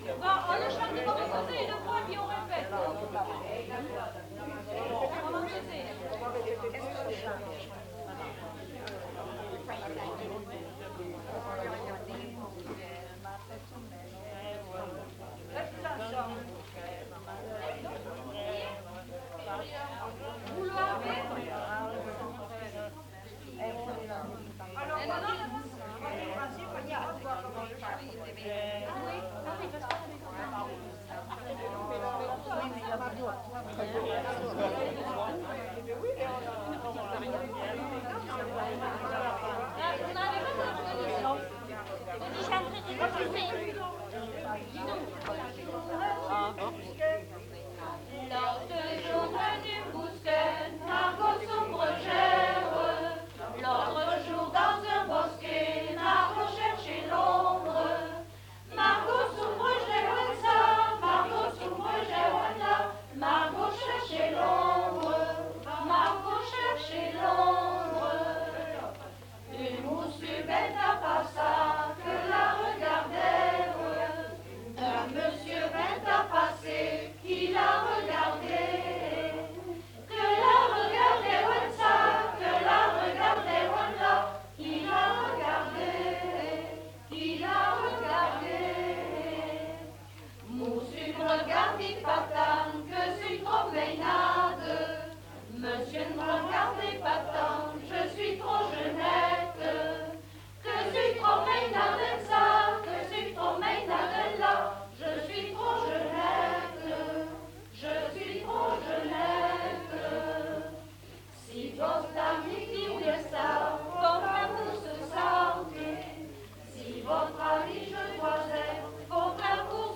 Aire culturelle : Grandes-Landes
Lieu : Salles
Genre : chant
Type de voix : voix de femme
Production du son : chanté
Notes consultables : Interprété par un ensemble vocal.